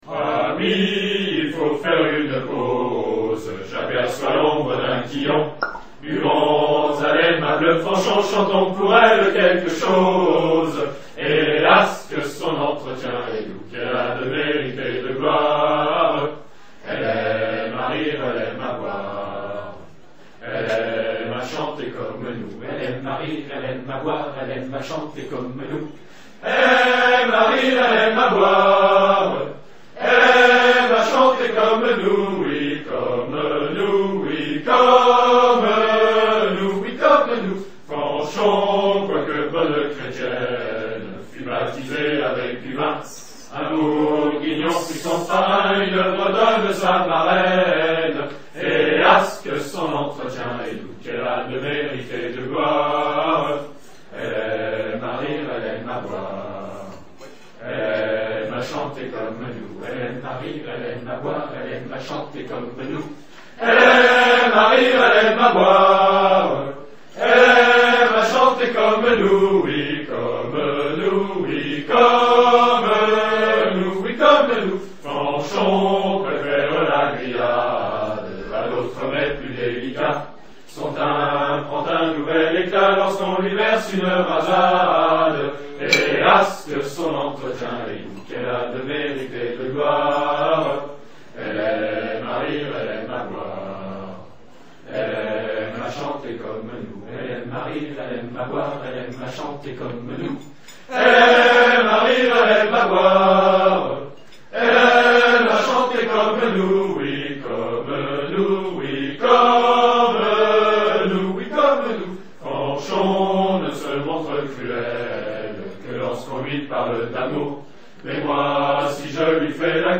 Chanson a boire